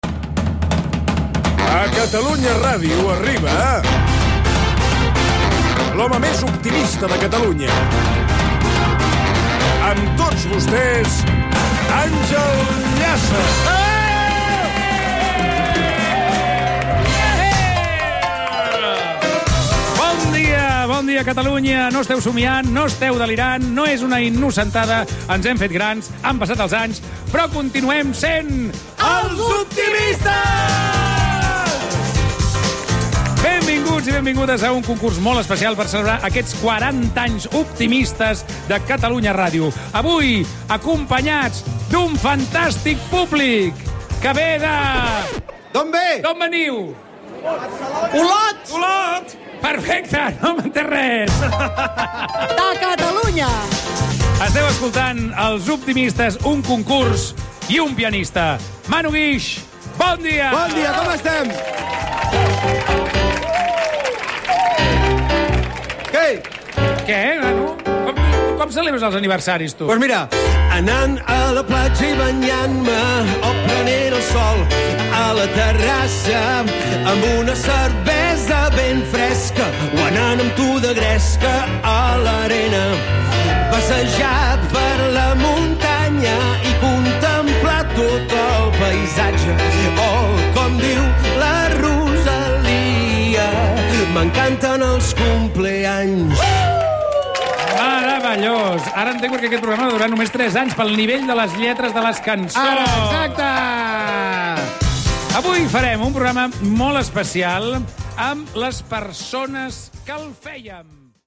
Careta del programa, presentació i interpretació del pianista i cantant Manu Guix
Entreteniment